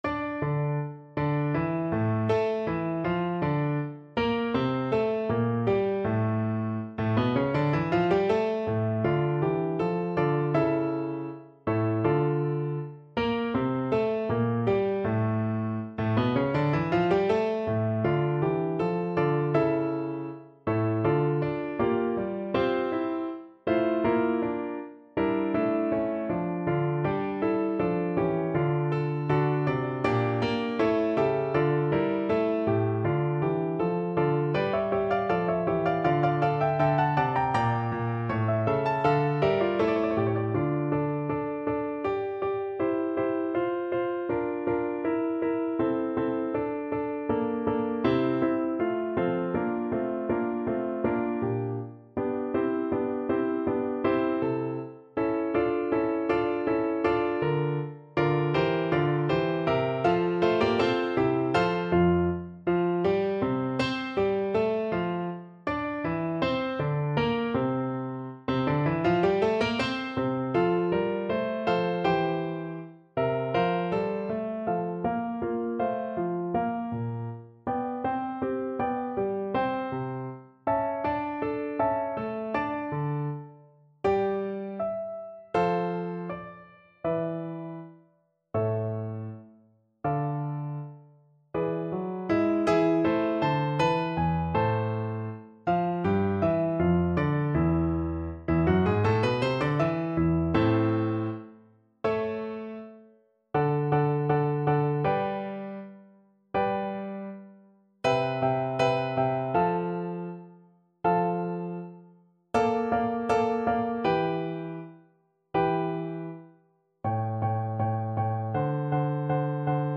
Andante e spiccato
F major (Sounding Pitch) (View more F major Music for Bassoon )
4/4 (View more 4/4 Music)
Classical (View more Classical Bassoon Music)